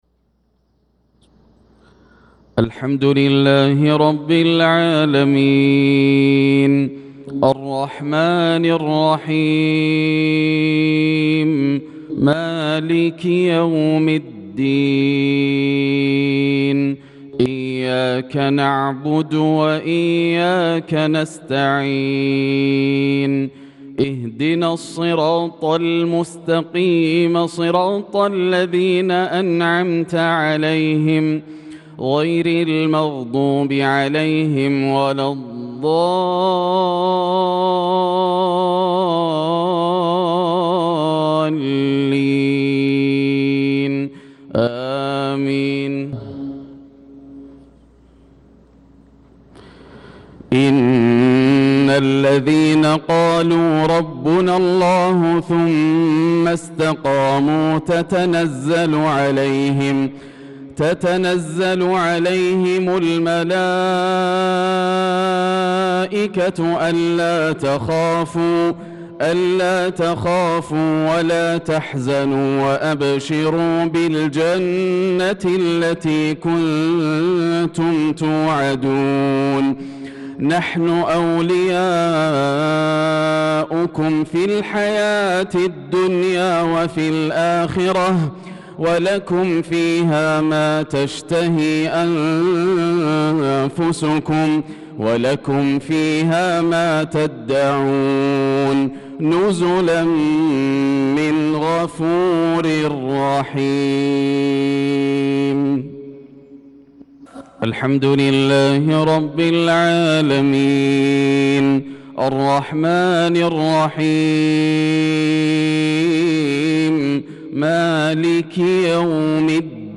صلاة المغرب للقارئ ياسر الدوسري 17 ذو القعدة 1445 هـ
تِلَاوَات الْحَرَمَيْن .